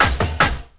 50LOOP02SD-R.wav